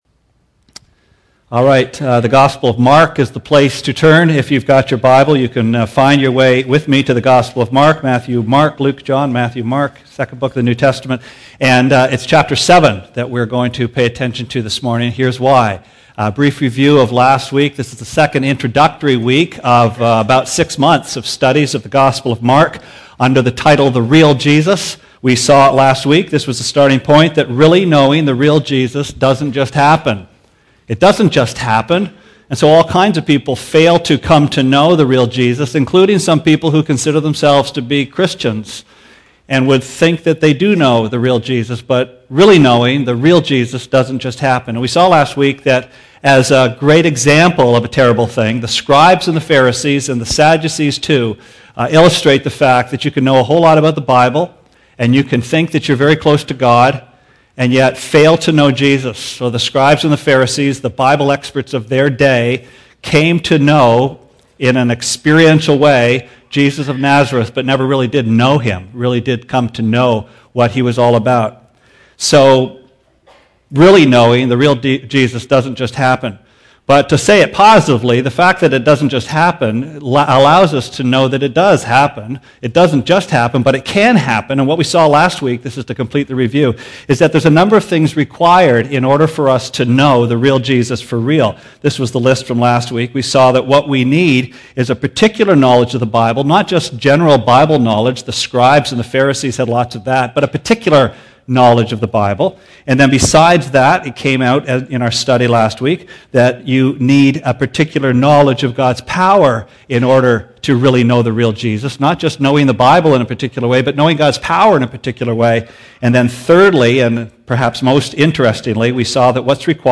Sermon Archives January 11, 2009: The Real Jesus: The Real Problem This morning we look at the second of two introductory sermons to begin a new series on the gospel of Mark entitled "The Real Jesus."